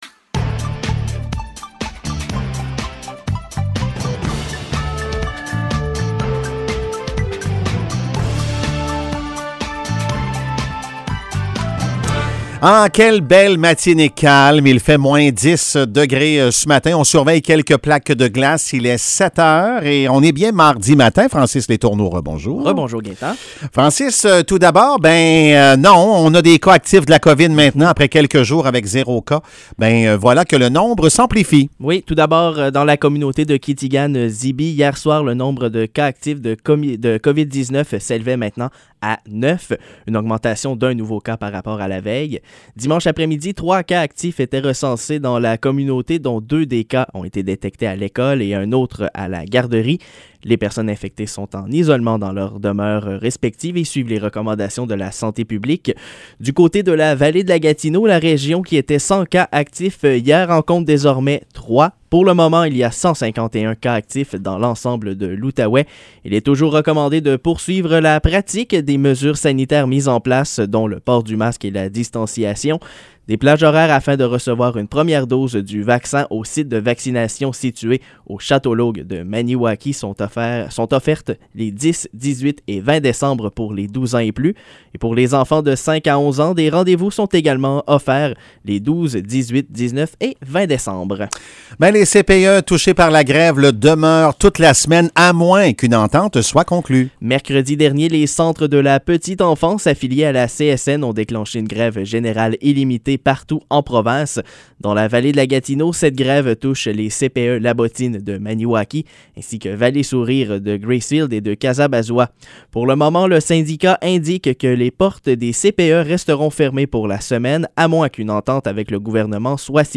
Nouvelles locales - 7 décembre 2021 - 7 h